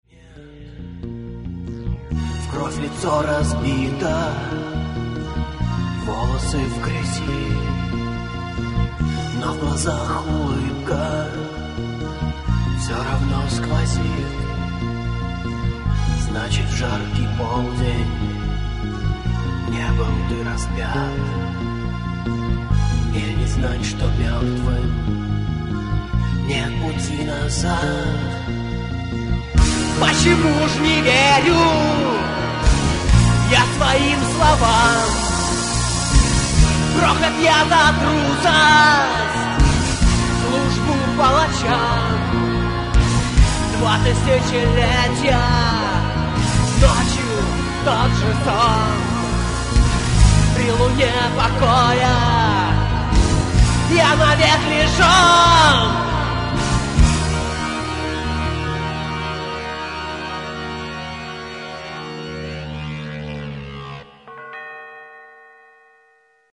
Клавиши, перкуссия, вокал
mono